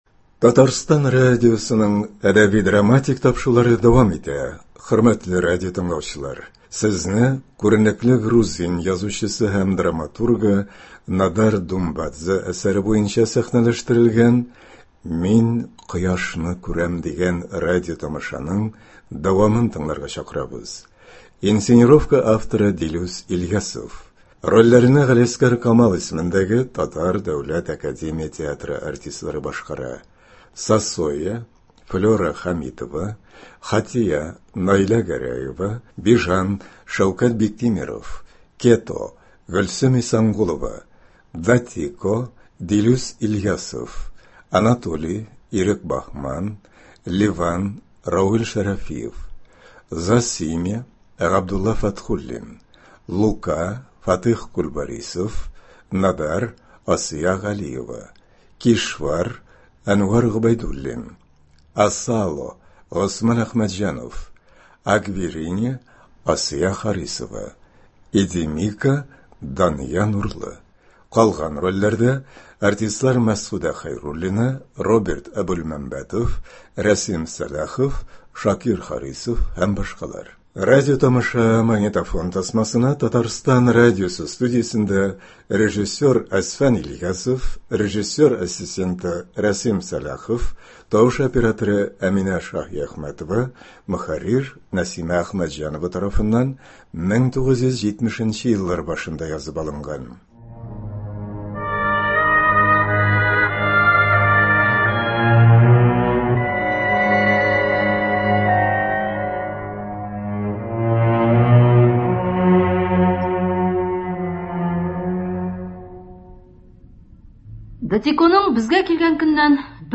Радиотамаша (14,15.10.23) | Вести Татарстан
Рольләрне Г.Камал исемендәге Татар дәүләт академия театры артистлары башкара.